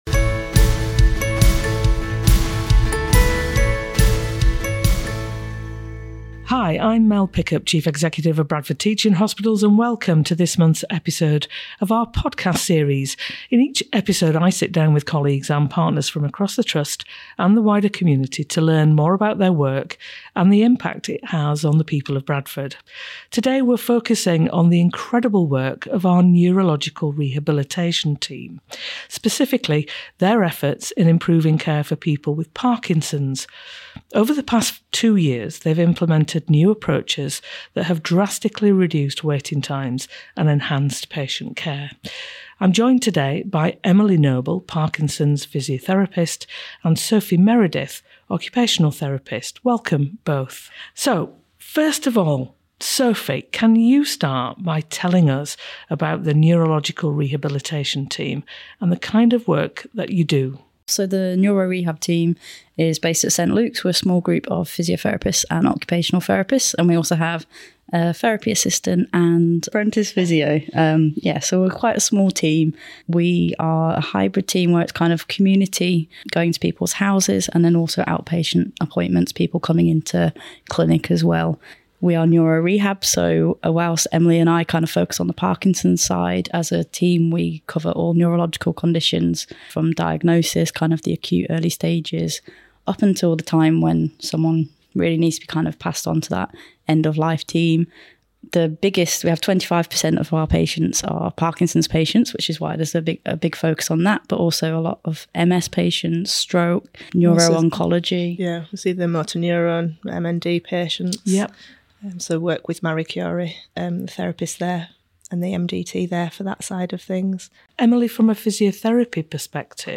compelling conversation